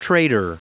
Prononciation du mot trader en anglais (fichier audio)
Prononciation du mot : trader